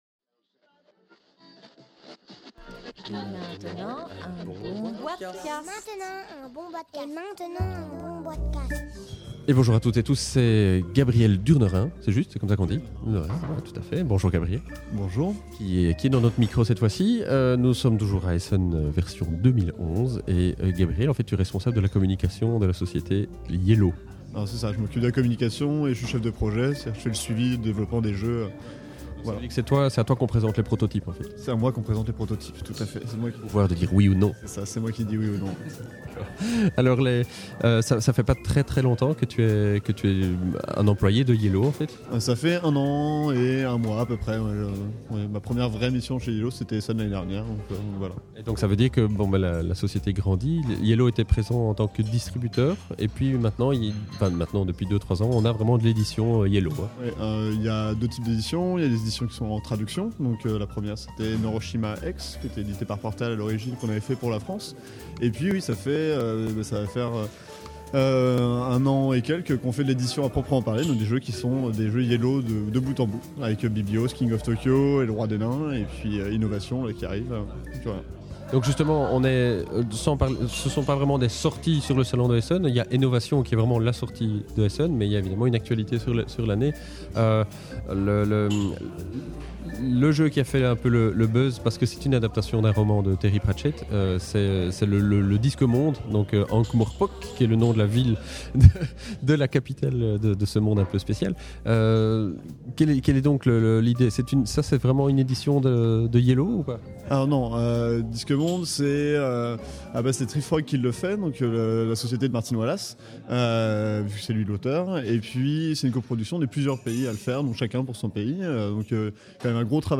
(enregistré au Salon international du Jeu de Société de Essen – Octobre 2011)